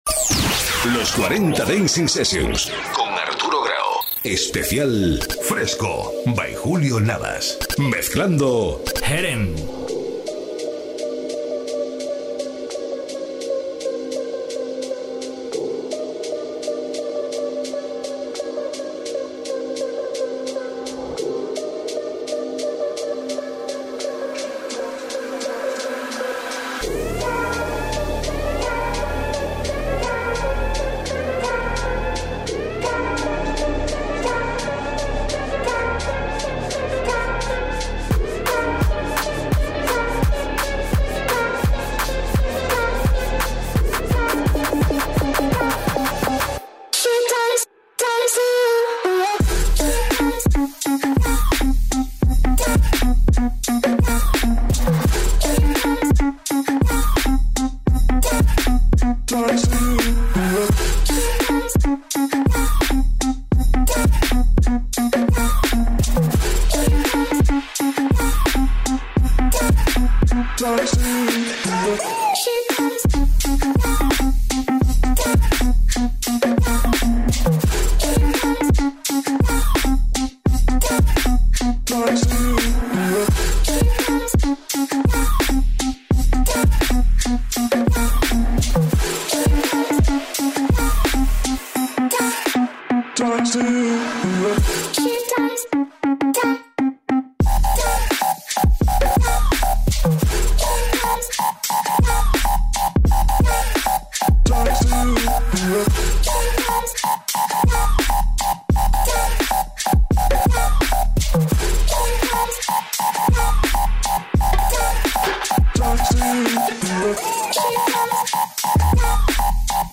Los sonidos más bailables de la electrónica mainstream.